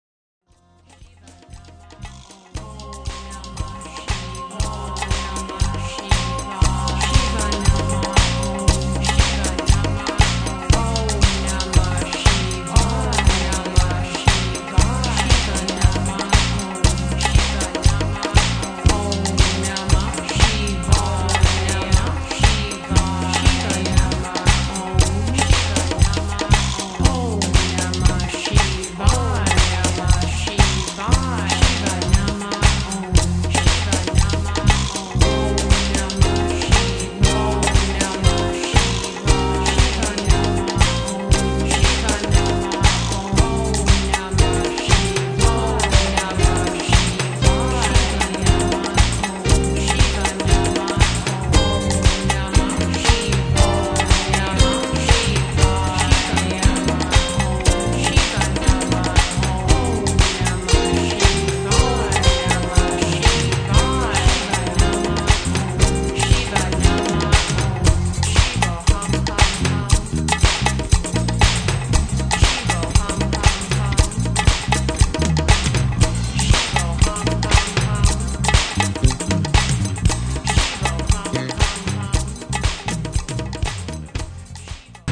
Lyrics from traditional chant.
Guitar, percussion and bass
Additional piano